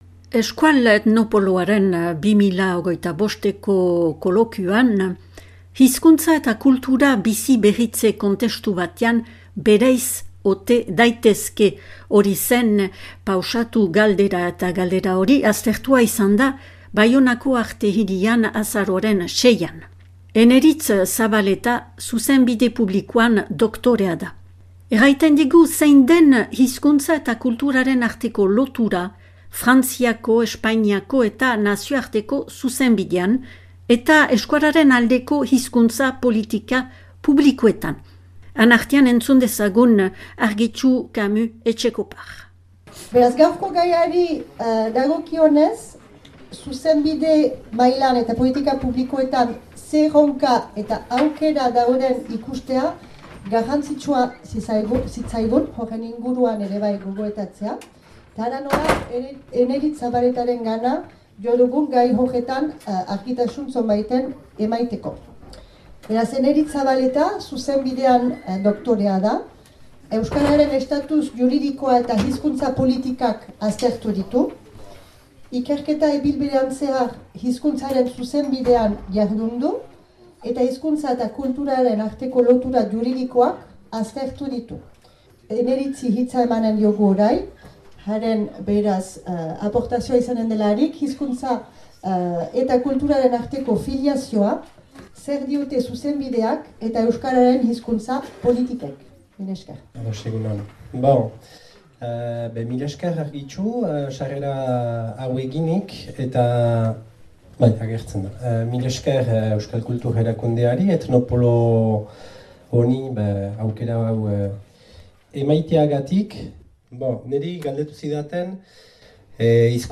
Hizkuntza eta kulturaren arteko harreman horren berrasmatzeaz ari izan zauzkigu Euskal Herriko Unibertsitateko bi irakasle-ikerlariak, Hazparneko Libertimendua aztertuz.